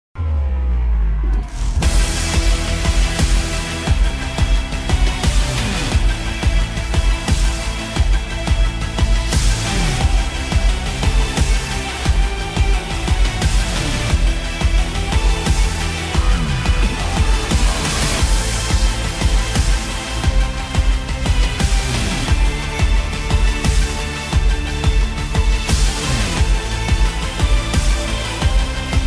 • Качество: 192, Stereo
Классная энергичная музыка из игры